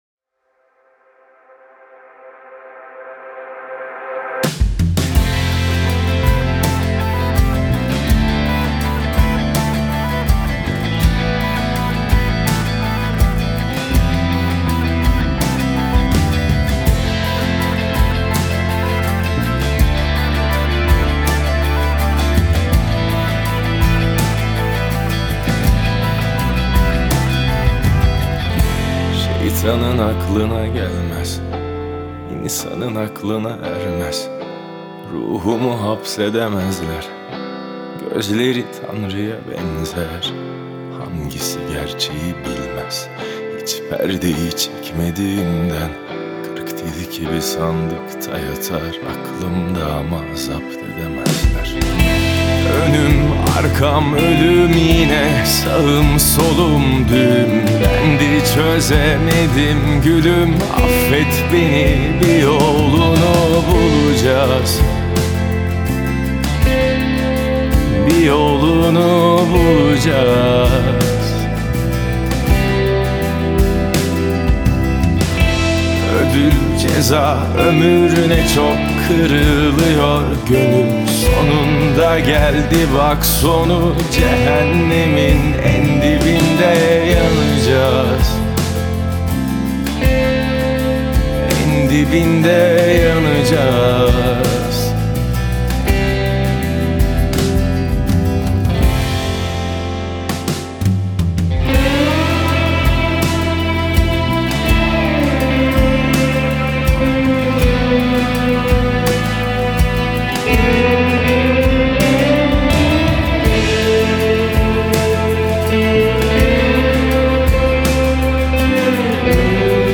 Трек размещён в разделе Турецкая музыка / Рок.